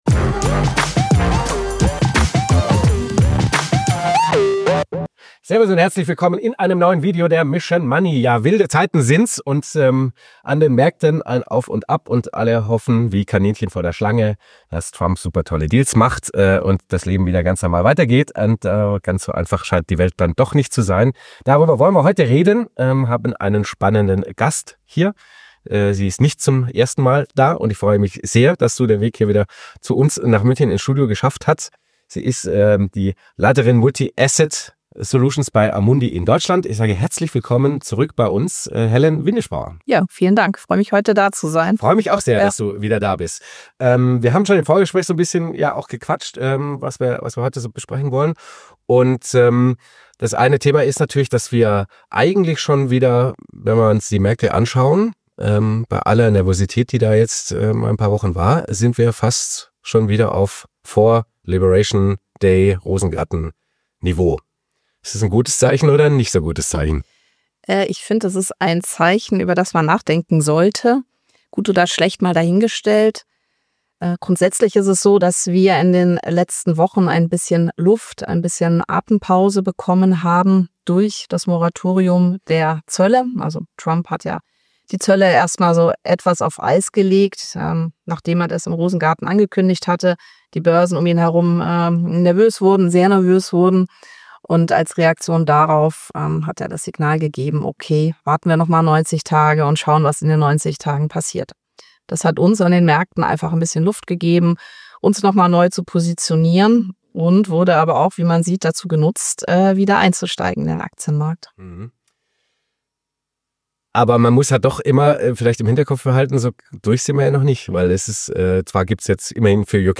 Wir befragen für dich jede Woche die besten Finanz- und Wirtschafts-Experten zu aktuellen Themen rund um dein Geld. powered by FOCUS MONEY
Interview